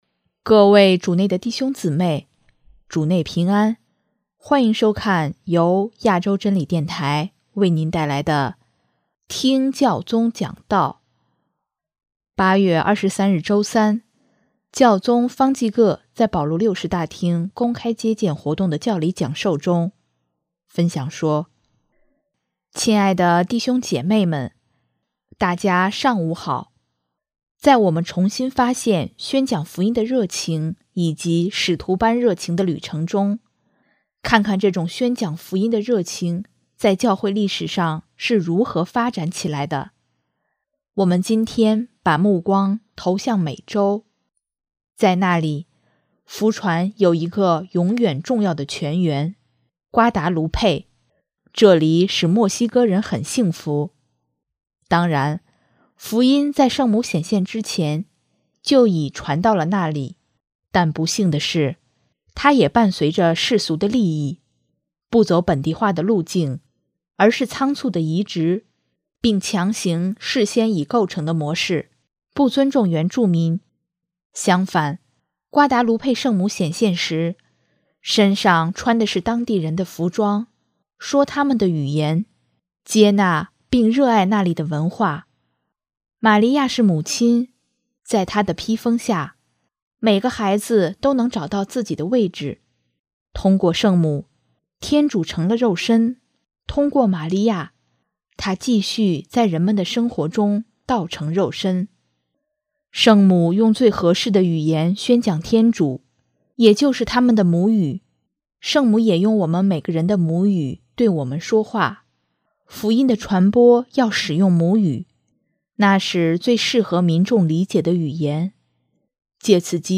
【听教宗讲道】|“我在这里，难道我不是你的母亲吗？”
8月23日周三，教宗方济各在保禄六世大厅公开接见活动的教理讲授中，分享说：